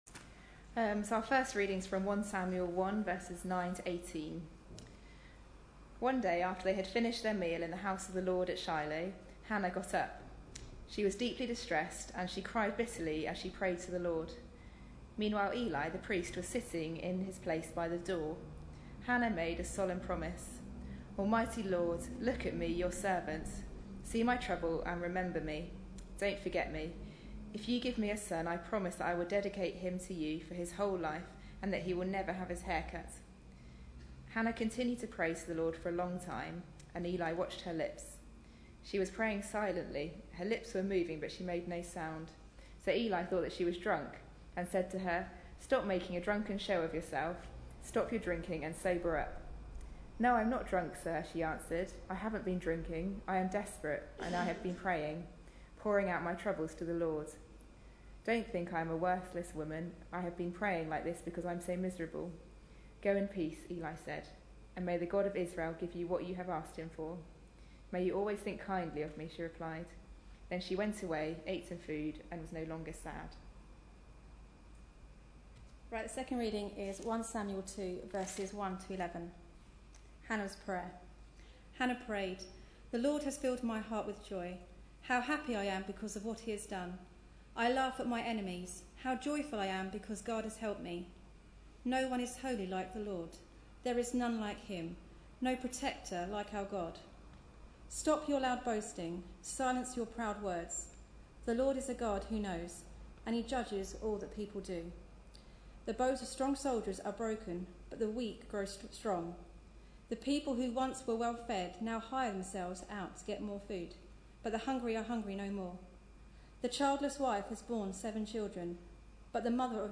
Hannah, a lady of prayer | Sermons | Central Baptist Church, Chelmsford